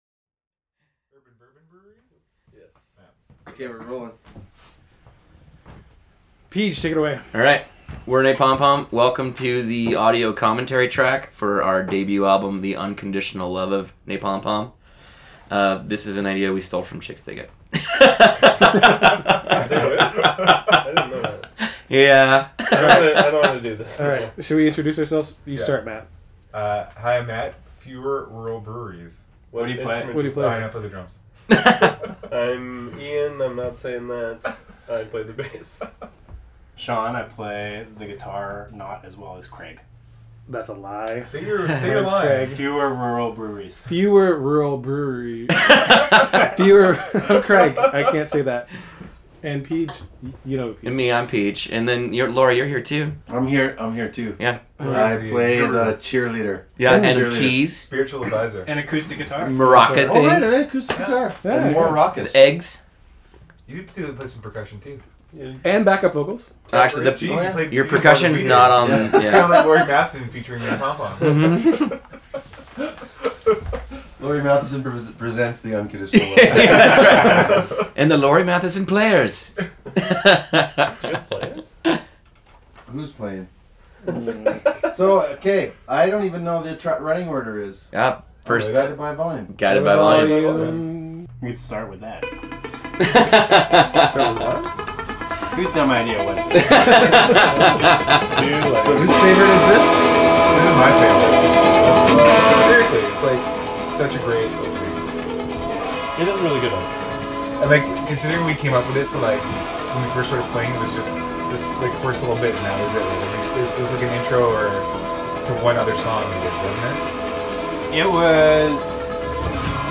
Download the web-only special audio commentary track here ! Yes, we stole Chixdiggit’s idea and talk (over each other) about the behind the scenes stories of the recording of the album.
six-idiots-talking-over-each.mp3